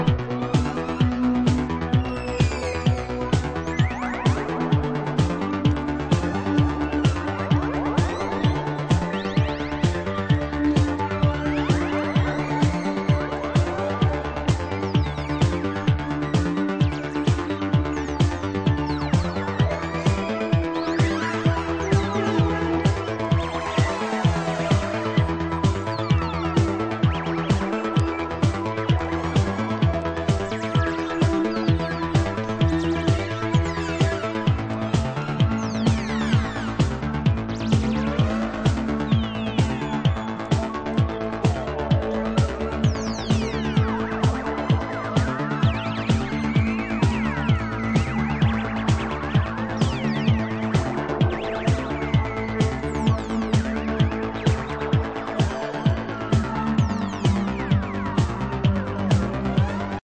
Moog classic